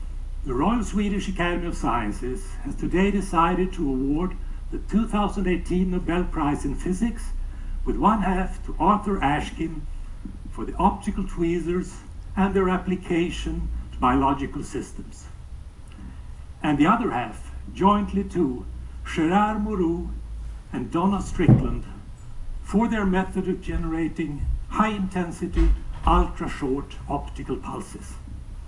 STOCKHOLM, Oct. 2(Greenpost) — Göran Hansson,  Permanent Secretary of the Royal Swedish Academy of Sciences has announced the result of the 2018 Nobel Prize in Physics at the headquarter of the Academy.